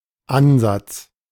In physics and mathematics, an ansatz (/ˈænsæts/; German: [ˈʔanzats]
De-Ansatz.ogg.mp3